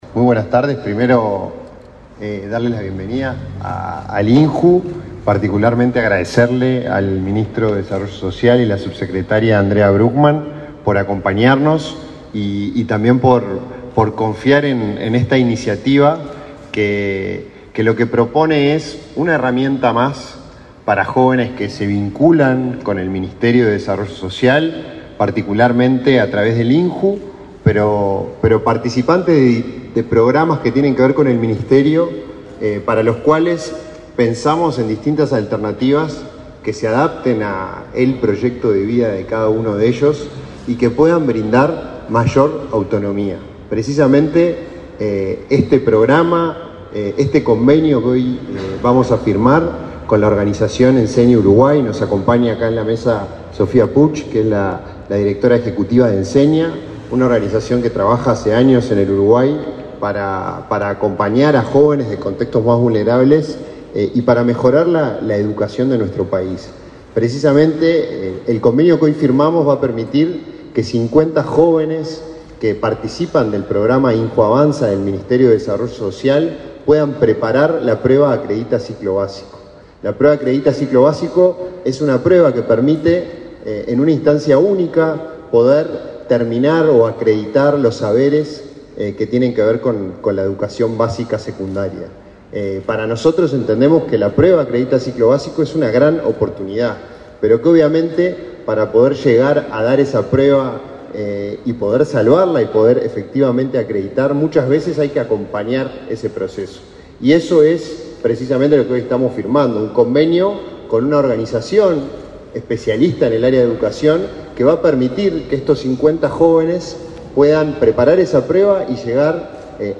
Conferencia de prensa por firma de convenio entre el INJU y la organización social Enseña Uruguay
Participaron del evento, el ministro del Mides, Martín Lema, y el director del INJU, Felipe Paullier.